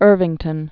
(ûrvĭng-tən)